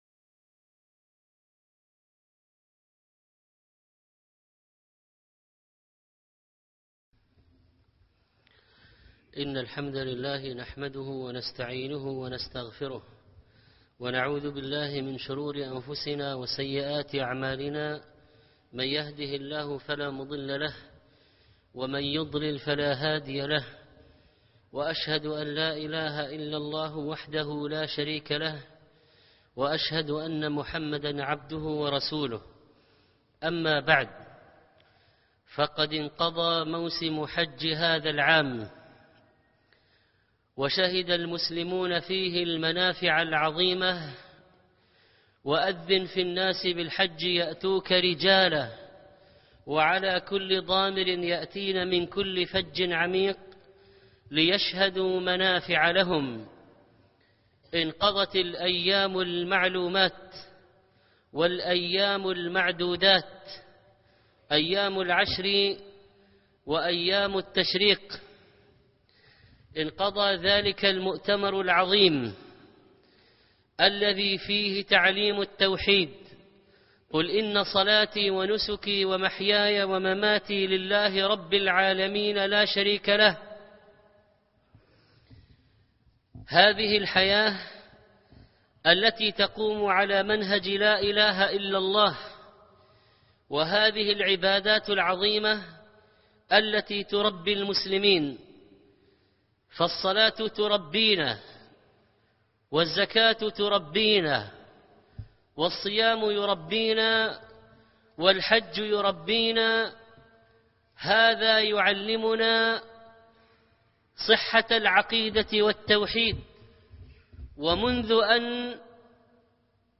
الحج إيمان وتعظيم - خطب الجمعة - الشيخ محمد صالح المنجد